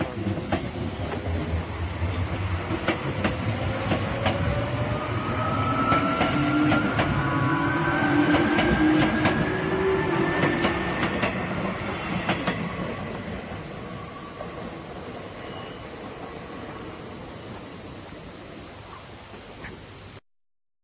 ８０００系起動音（大井町線　緑が丘）